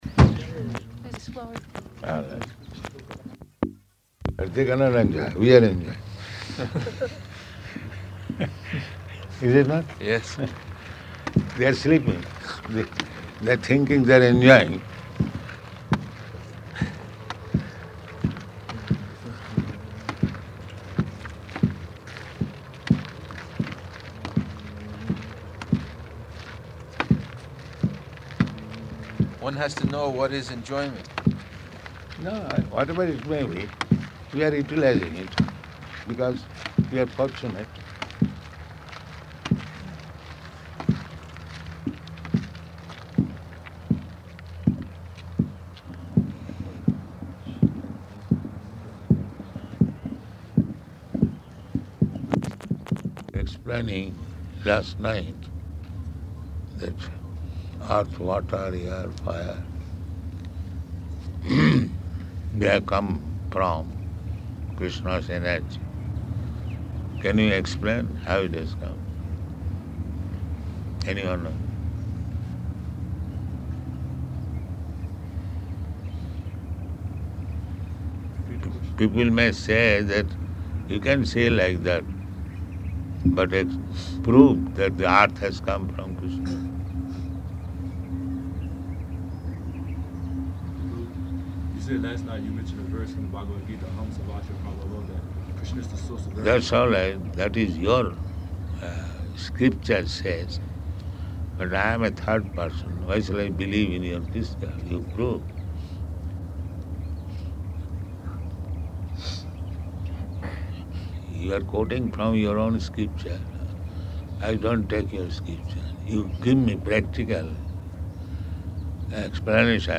Morning Walk --:-- --:-- Type: Walk Dated: November 1st 1975 Location: Nairobi Audio file: 751101MW.NAI.mp3 Girl devotee: ...lotus flower.